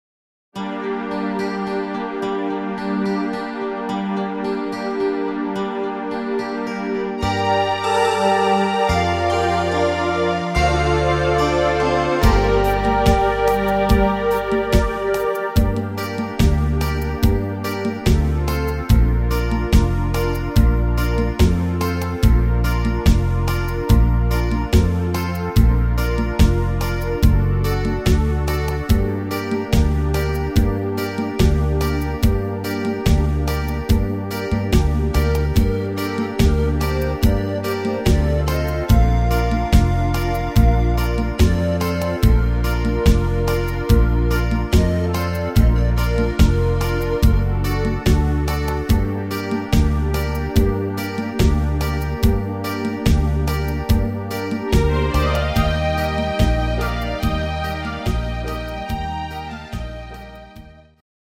instr. Panflöte